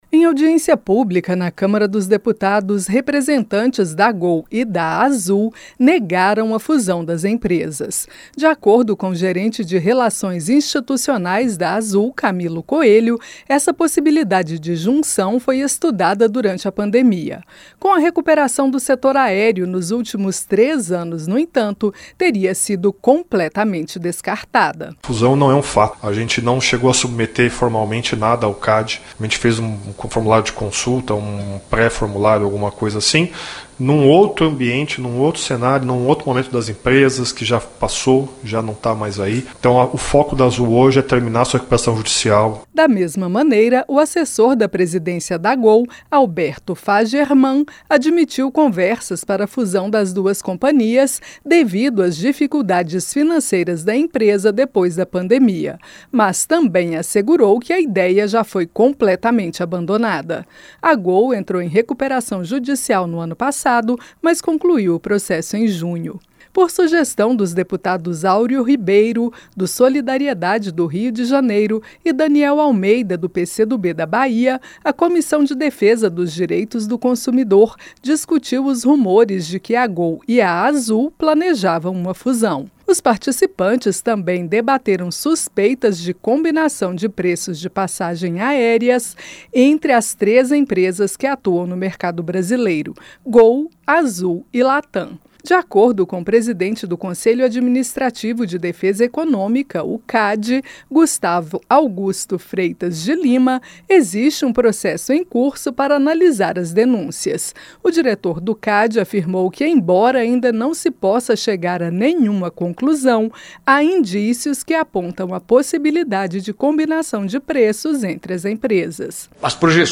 AZUL E GOL NEGAM PROCESSO FUSÃO EM AUDIÊNCIA NA CÂMARA. A REPÓRTER